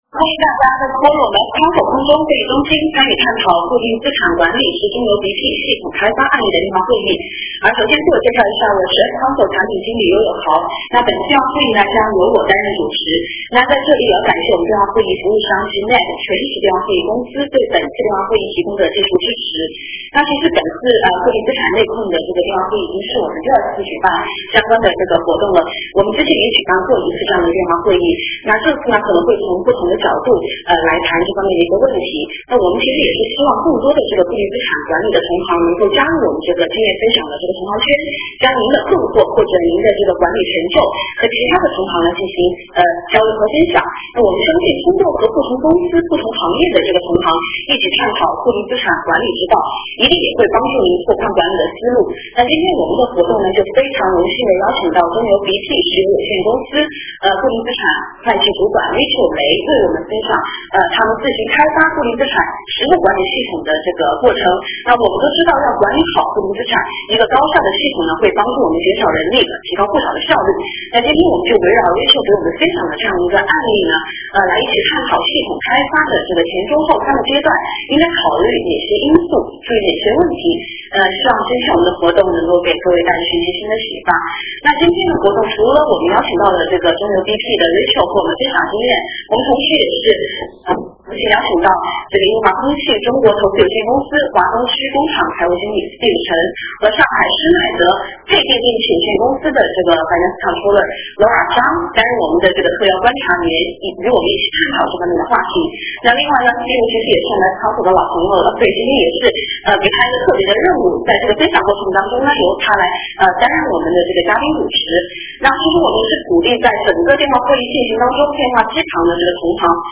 电话会议
Q&A 环节